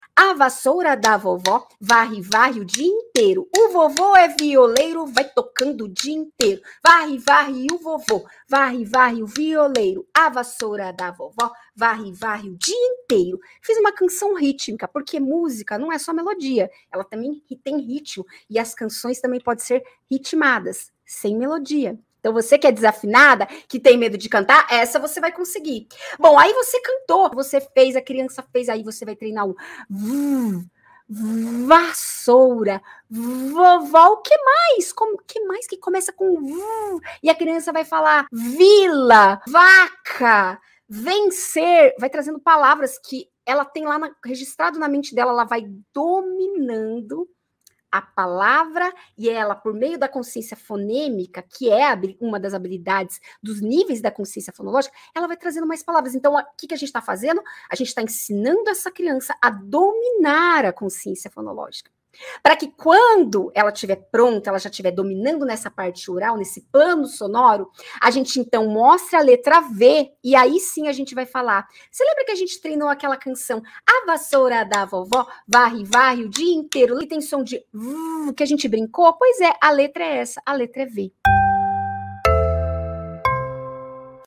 uma canção Rítmica